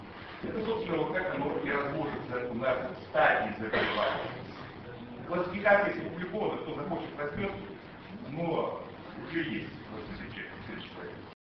Съезд Ассоциации Урологов Дона с международным участием. Ростов-на-Дону, 27-28 октября 2004 года.
Лекция: "Прогностические факторы при раке предстательной железы".